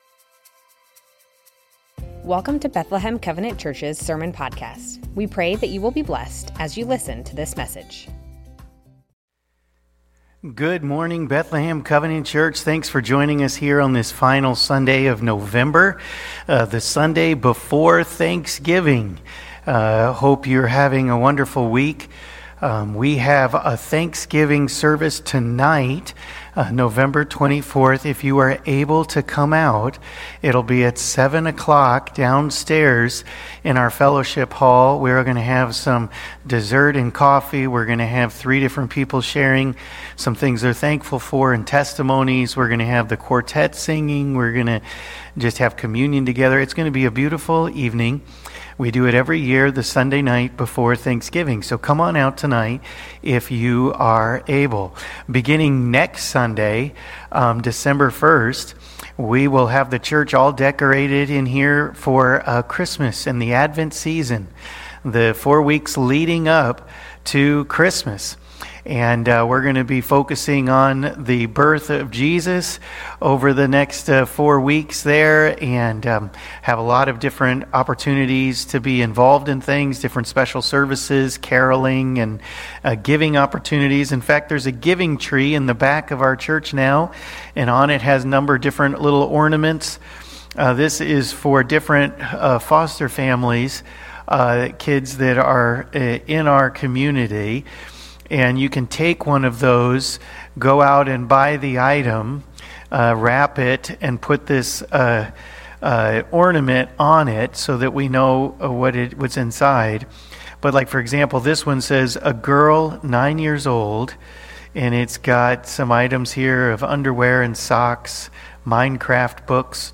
Bethlehem Covenant Church Sermons What it means to be thankful Nov 24 2024 | 00:37:15 1x 00:00 / 00:37:15 Subscribe Share Spotify RSS Feed Share Link Embed